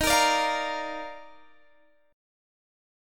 D#M7sus2 chord